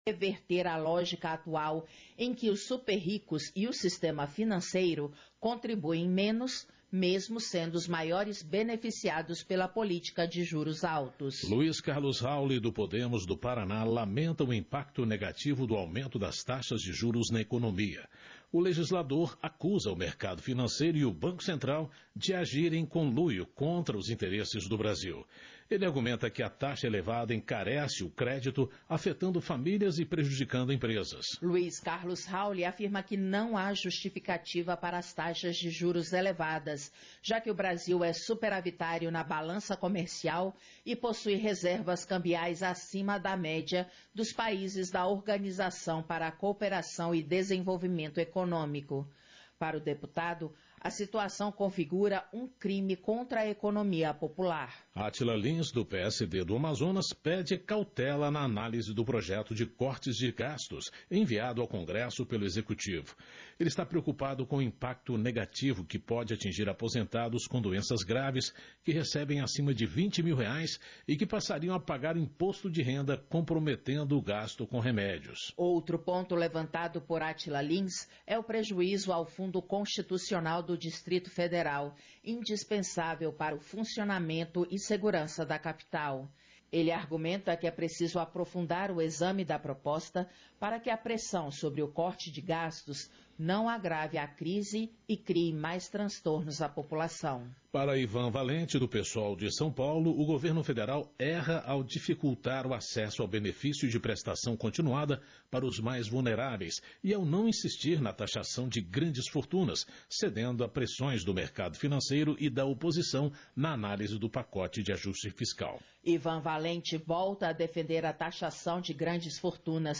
Audio da sessão ordinária 40/2024